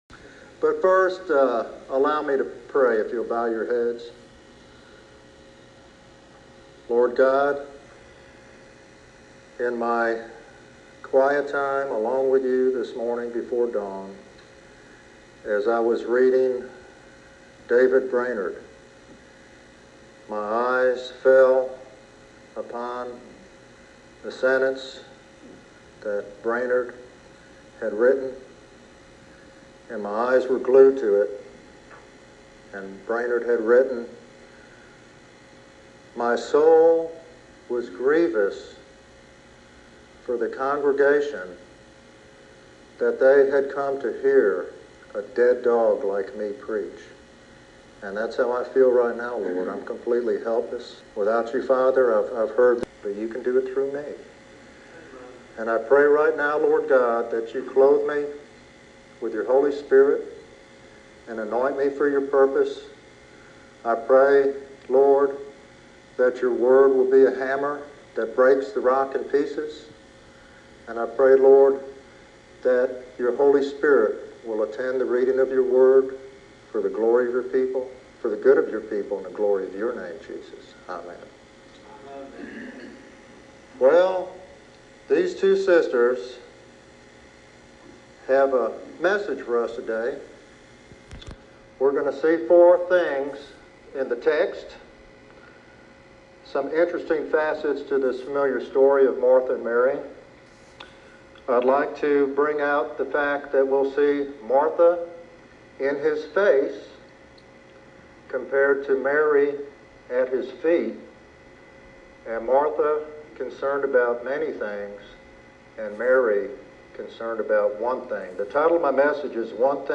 This sermon challenges listeners to evaluate their own priorities and embrace the 'one thing needful'—a close, attentive relationship with Jesus.